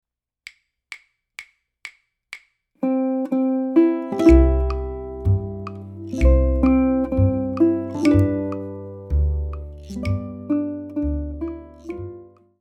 To play the pickup, count 1-2-3-4-1 and start the first pickup note on beat 2.
Pickup Note Example | Pickup notes in The Banks of the Ohio.